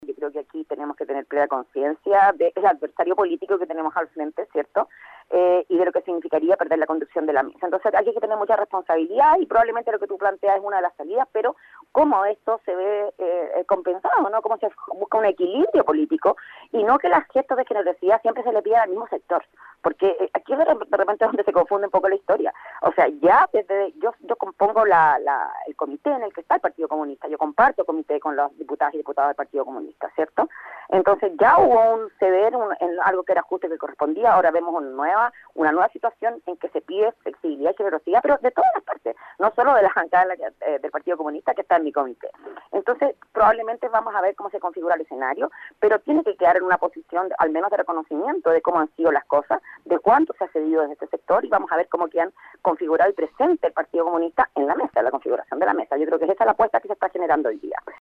Al respecto, en Nuestra Pauta conversamos con la diputada del Distrito 12 y presidenta de la Comisión de Salud, Ana María Gazmuri (PAH), quién recordó que la vez pasada no se respetó el acuerdo previo y la Oposición bloqueó al Partido Comunista para que la diputada Karol Cariola no llegara a presidir la Cámara Baja.